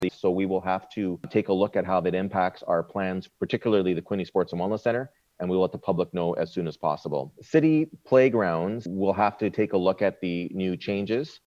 Speaking at city council’s virtual meeting on Monday, just hours after the province announced the loosening of some lockdown rules, Mayor Mitch Panciuk outlined some of the changes.